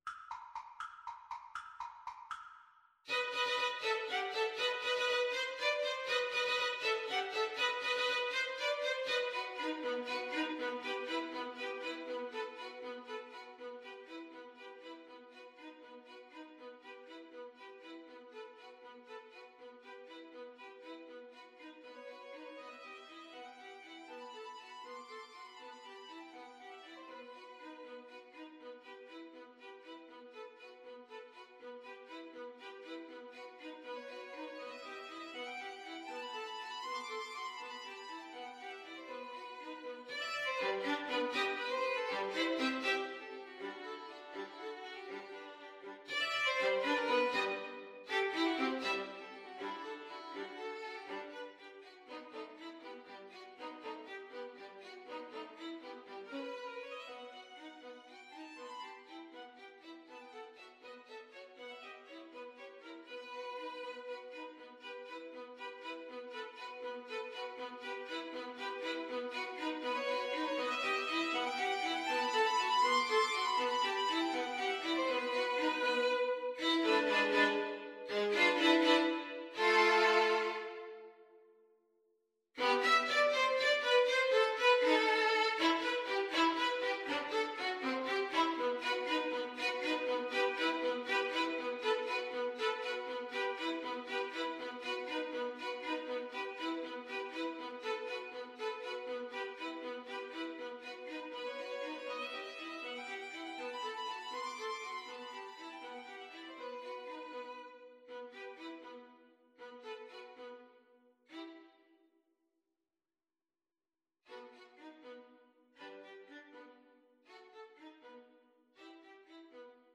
Allegro vivo (.=80) (View more music marked Allegro)
Violin Trio  (View more Advanced Violin Trio Music)
Classical (View more Classical Violin Trio Music)